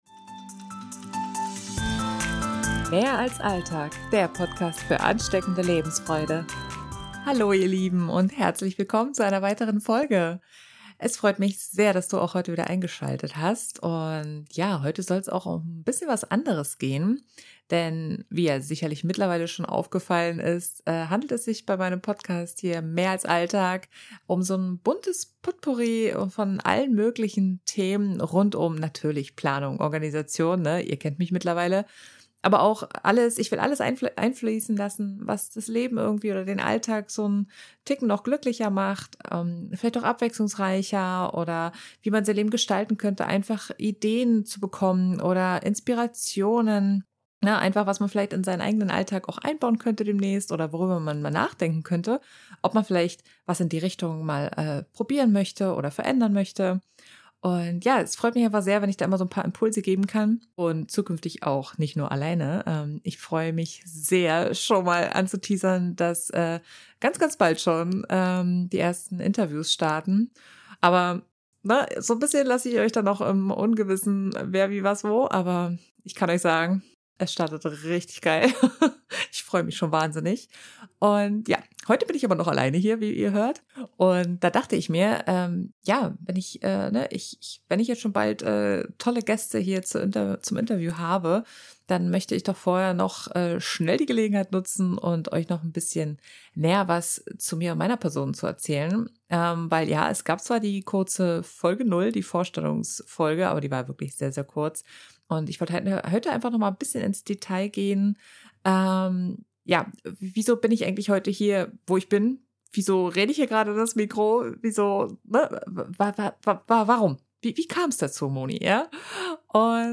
Intro-/Outromusik des Podcasts